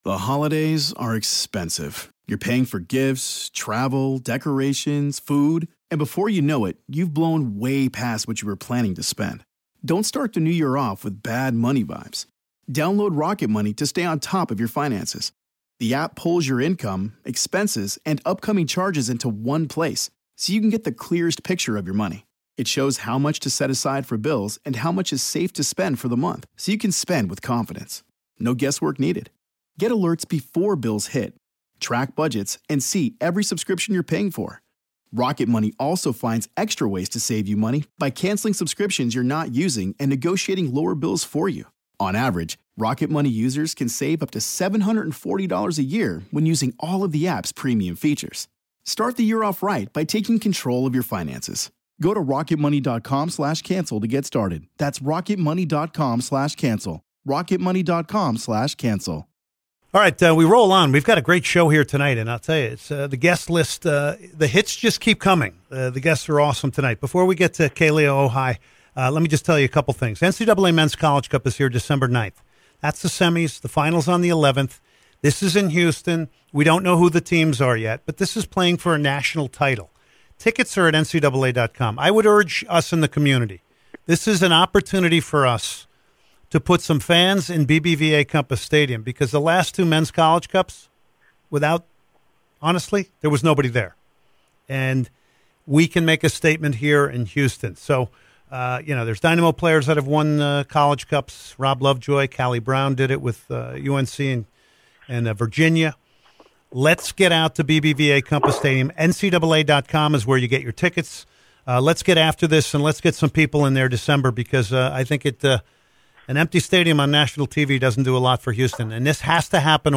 Bonus Interview with Kealia Ohai